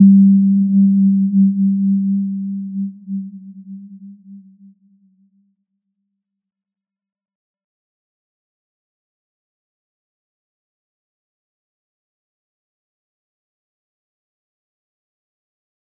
Little-Pluck-G3-p.wav